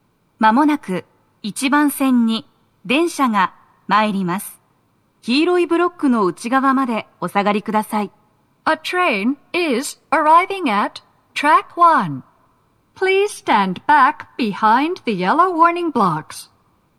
スピーカー種類 TOA天井型
鳴動は、やや遅めです。
接近放送2
放送は「簡易放送」です。